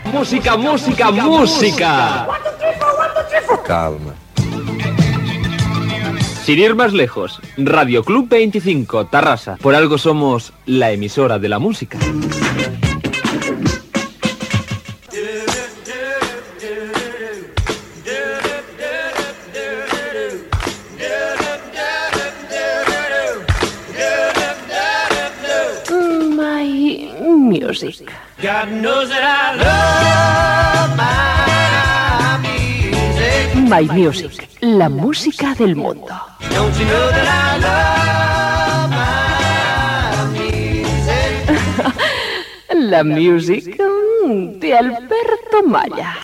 Careta del programa
Musical
FM